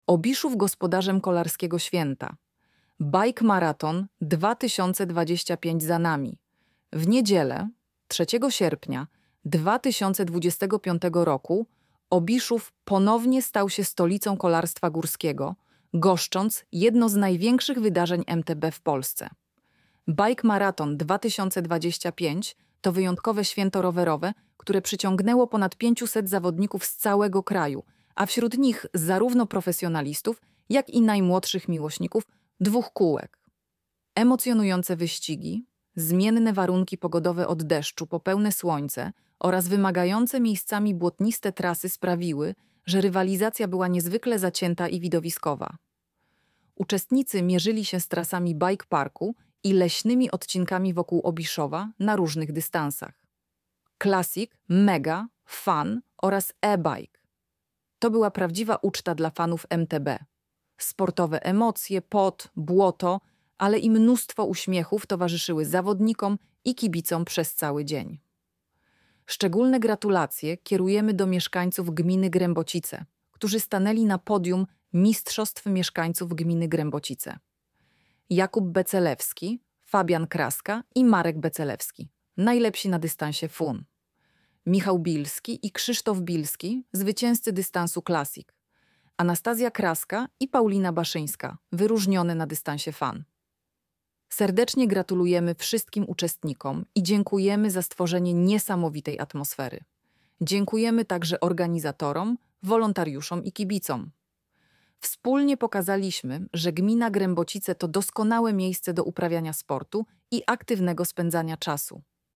Lektor-MTB.mp3